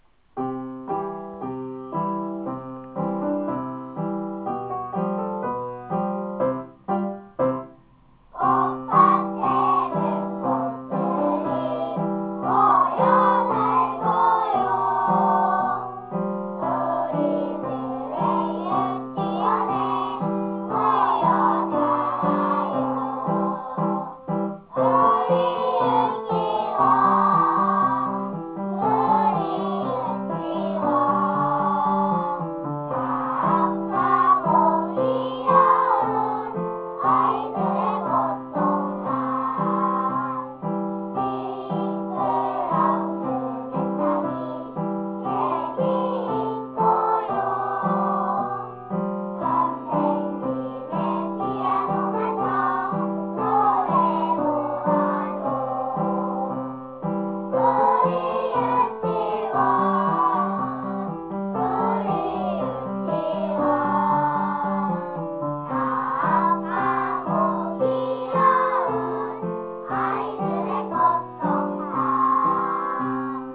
원가 악보보기 원가듣기 유치원 캐릭터 본 원을 상징하는 캐릭터입니다.